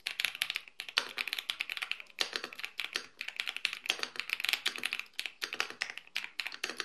Przełączniki, użytkowanie i dźwięk.
Epomaker Cypher 81 w wersji z liniowymi przełącznikami Wisteria V1 to model, który wyraźnie celuje w wymagających użytkowników ceniących wysoką kulturę pracy oraz doskonałą responsywność.
Dźwięk jest przyjemnie basowy, głęboki i wyraźnie wyciszony, pozbawiony niepożądanych zjawisk akustycznych, takich jak pingowanie czy rezonans. Taki profil akustyczny to efekt zastosowania kilku warstw dźwiękochłonnych materiałów. Stabilizatory są fabrycznie przesmarowane co przekłada się na ich płynne działanie – bez efektu grzechotania czy luźnych, rozmazanych uderzeń.
klawiatura-Epomaker-Cypher-81-dzwiek.mp3